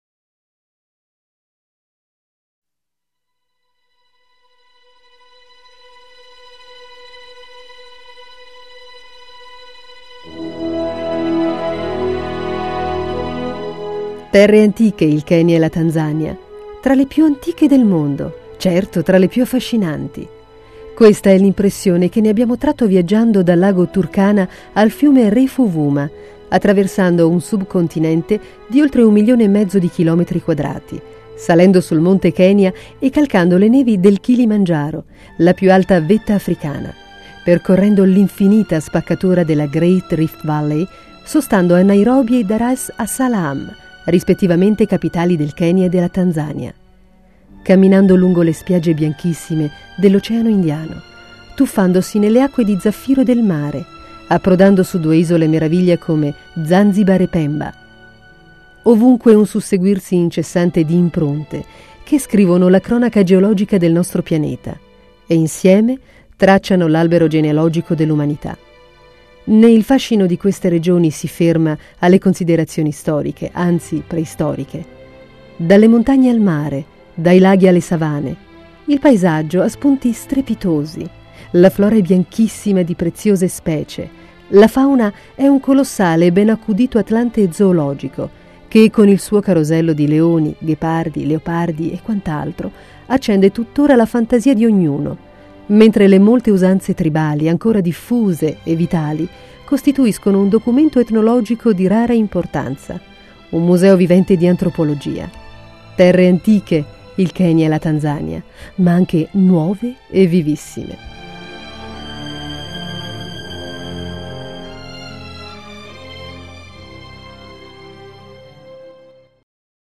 Sprecherin italienisch. voce narrante nazionale per RAI, SKY, audiolibri, audioguide
Sprechprobe: Sonstiges (Muttersprache):
female italian voice over artist. voce narrante nazionale per RAI, SKY, audiolibri, audioguide